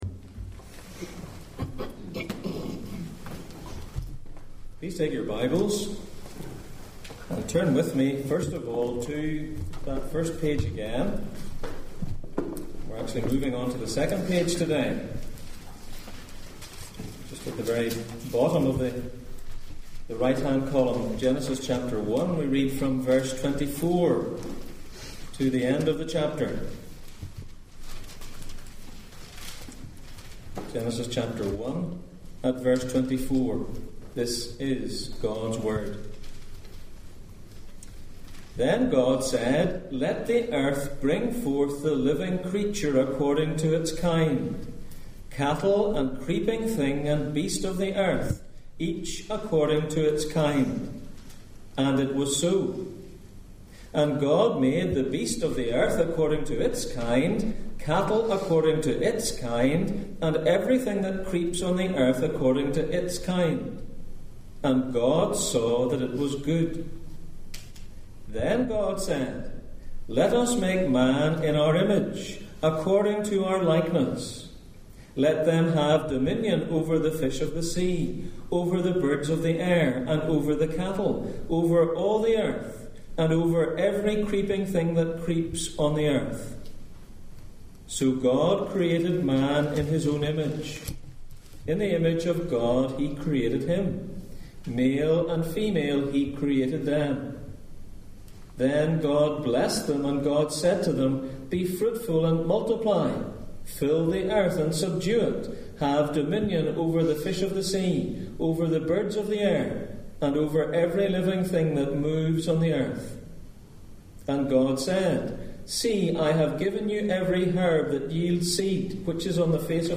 Back to the beginning Passage: Genesis 1:24-31, Deuteronomy 6:4-9, Job 40:15-24 Service Type: Sunday Morning